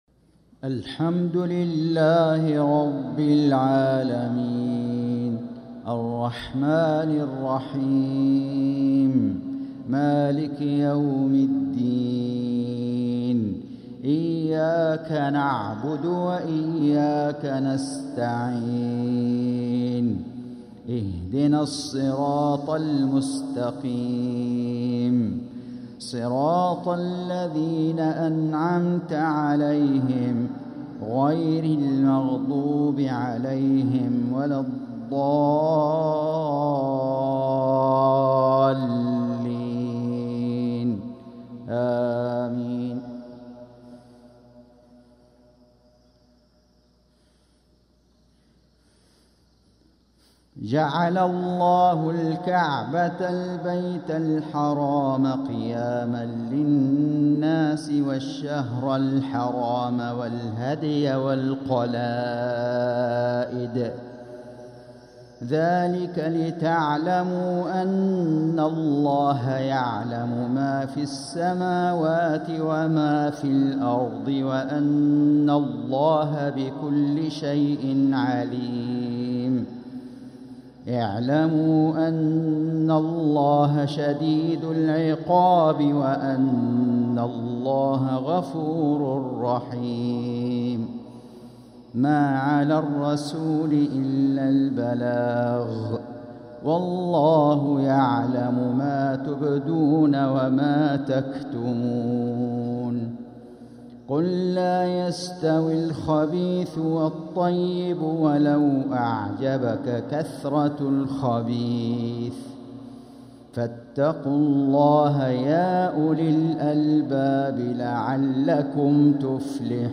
صلاة المغرب ٦-٧-١٤٤٦هـ | من سورة المائدة 97-100 و التوبة 36-37 | Maghrib prayer from Surah al-Ma'idah & at-Taubah | 6-1-2025 🎙 > 1446 🕋 > الفروض - تلاوات الحرمين